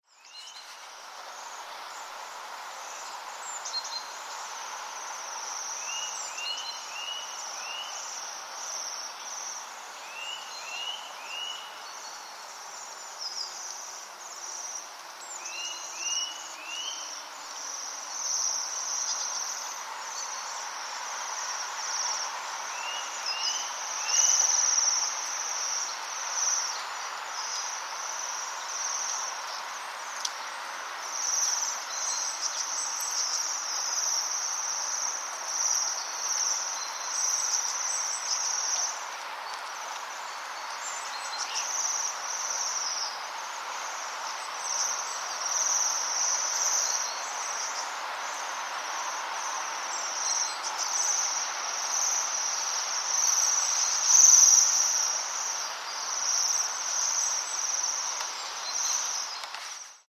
На этой странице собраны записи пения свиристелей – птиц с удивительно нежным и мелодичным голосом.
Звуки пения свиристелей в дикой природе (богемский воскокрыл)